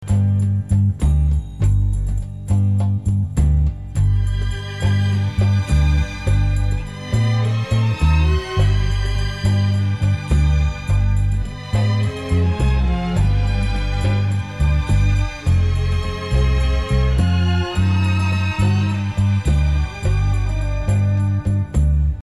• Noteu que el segon fitxer de so s'inicia de manera brusca.